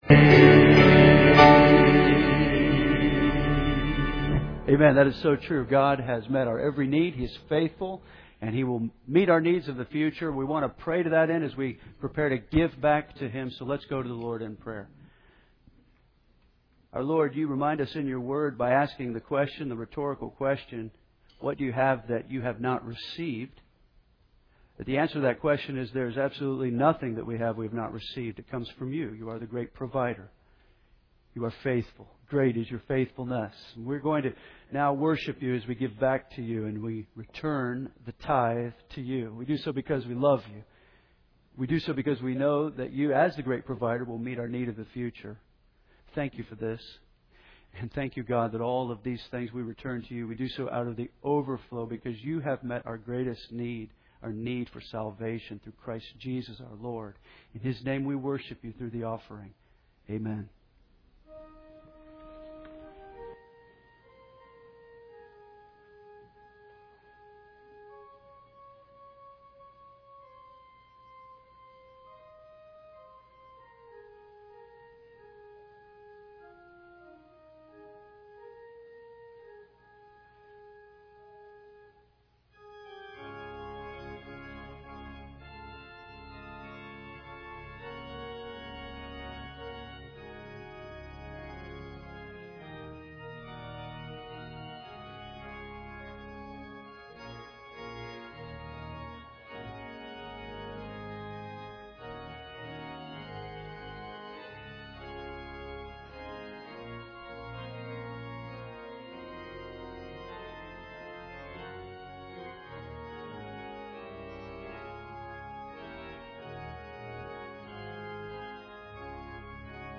Henderson’s First Baptist Church, Henderson KY